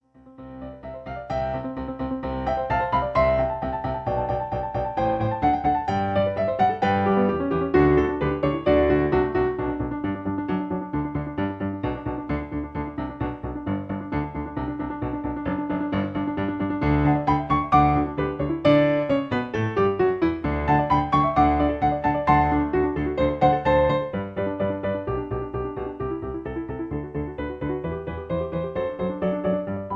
Original Key (C) Piano Accompaniment